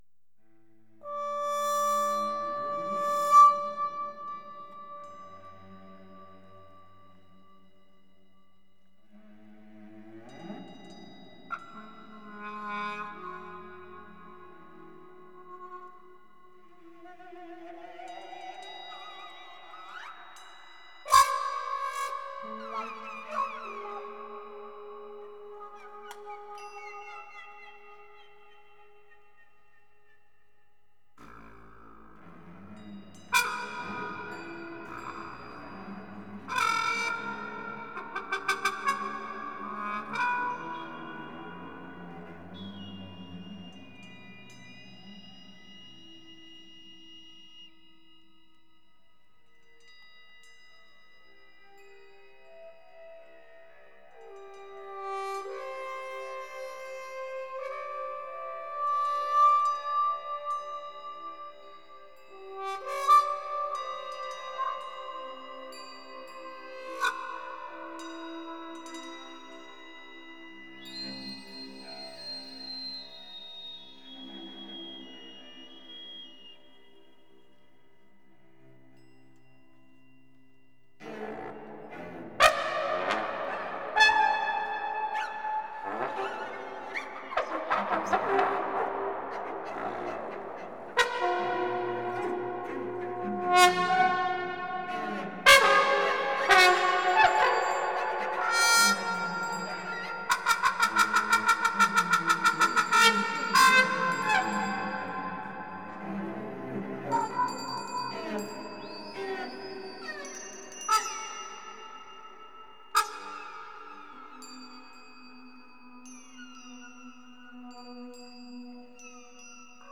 Bass Trumpet
Tibetian Bells
Violoncello 4.